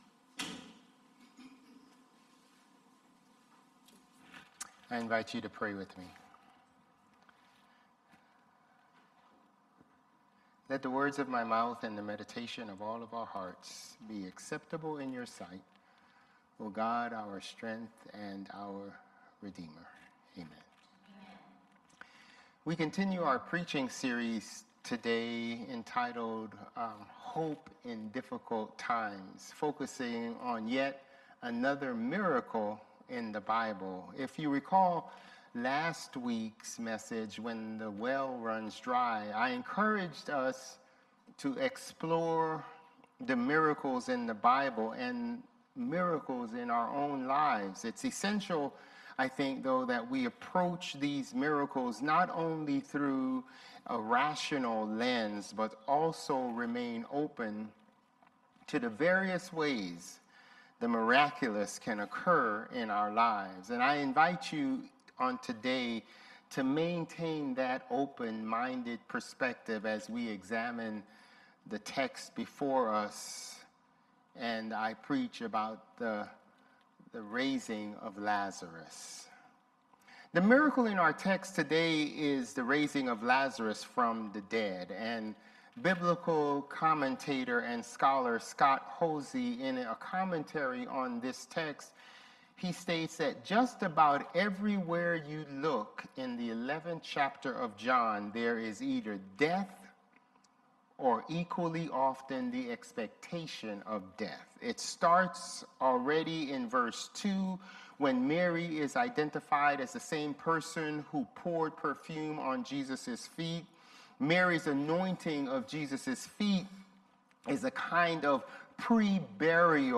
Sermons | Bethel Lutheran Church
September 14 Worship